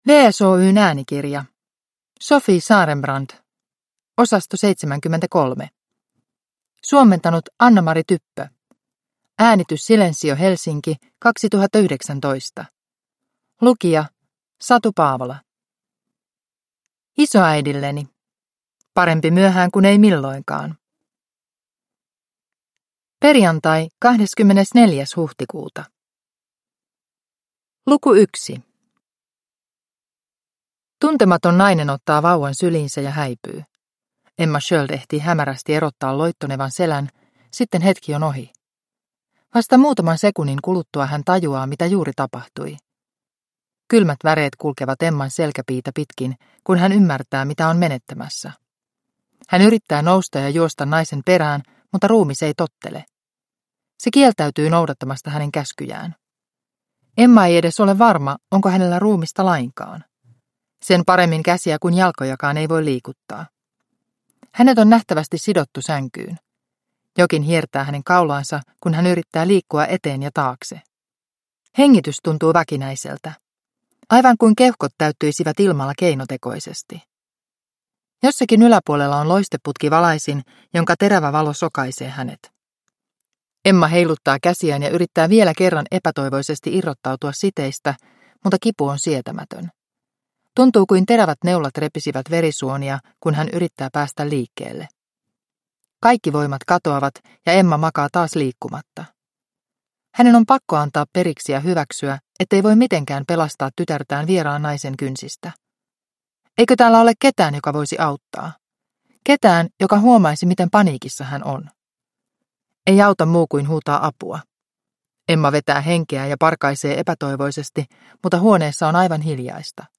Osasto 73 – Ljudbok – Laddas ner